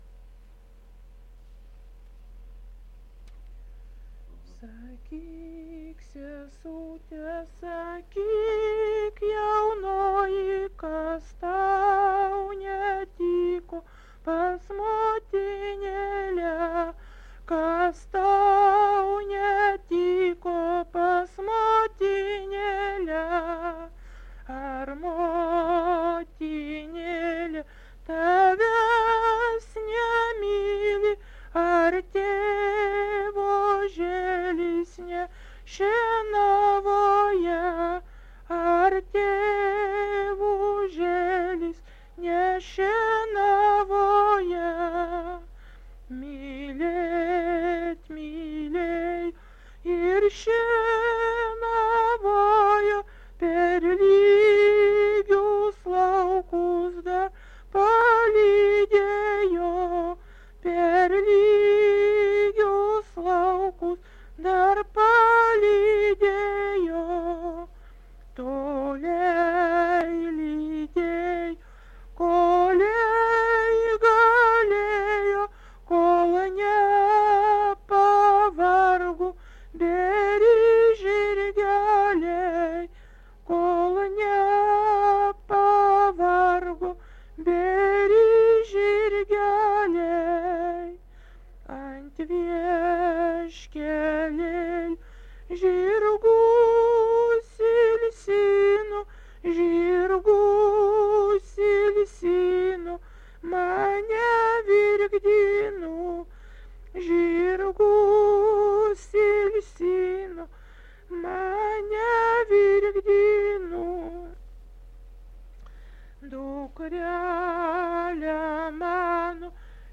Pasakojimas